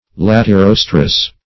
Search Result for " latirostrous" : The Collaborative International Dictionary of English v.0.48: Latirostral \Lat`i*ros"tral\, Latirostrous \Lat`i*ros"trous\, a. [Cf. F. latirostre.